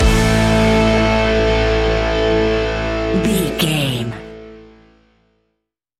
Ionian/Major
hard rock
heavy metal
instrumentals